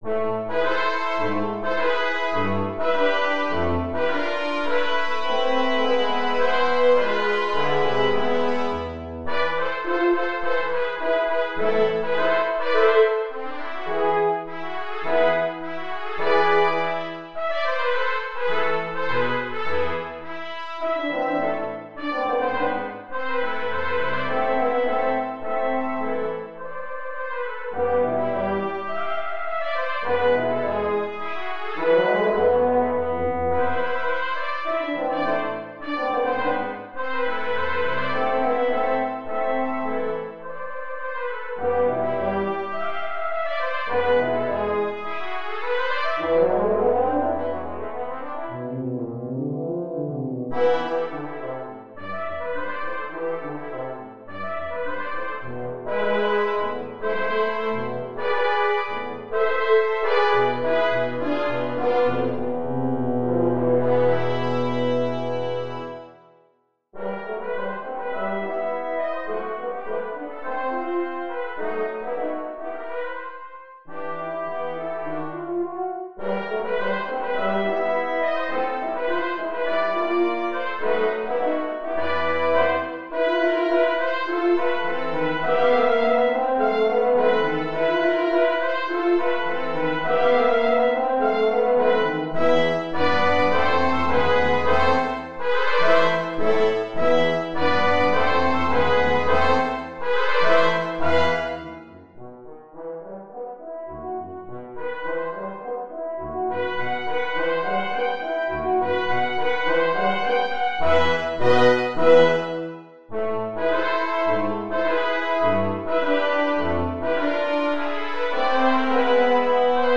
Mercia Brass Quintet No. 2 Royal Wedding (MIDI)